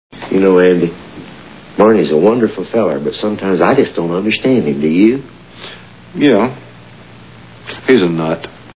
Andy Griffith Show Sound Bites (Page 3 of 4)